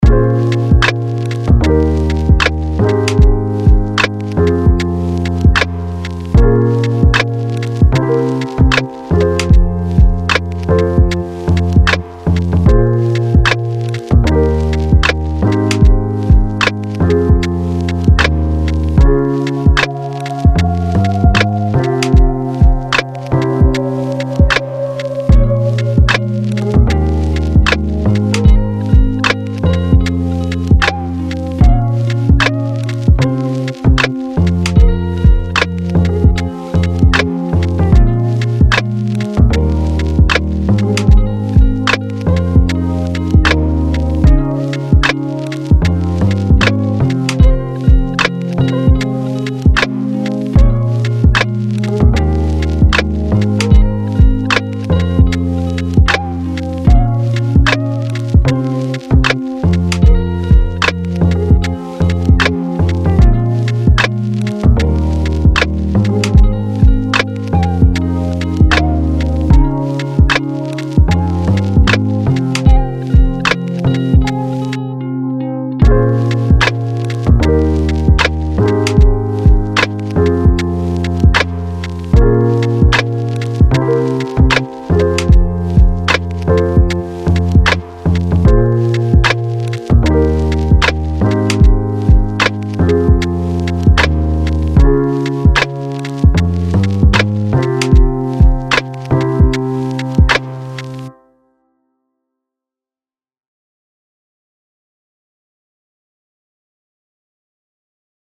おしゃれ しっとり FREE BGM